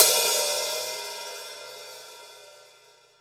paiste hi hat1 open.wav